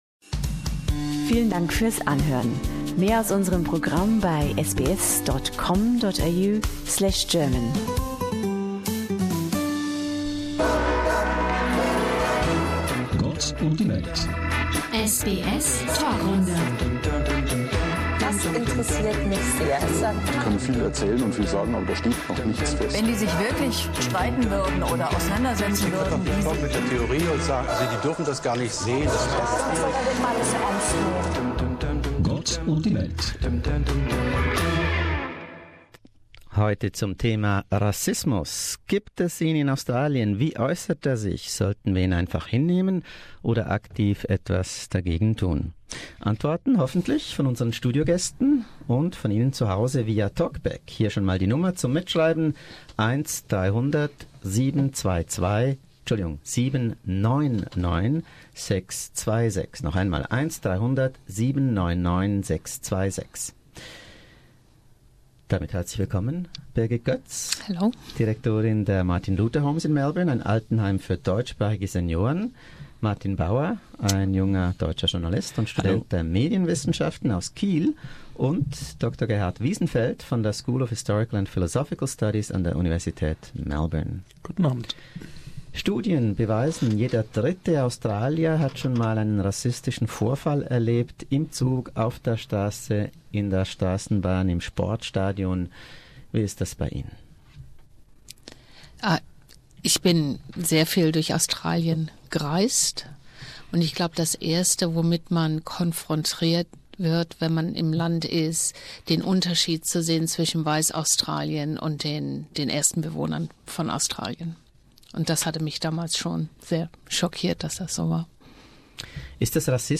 SBS panel discussion: Is Australia racist?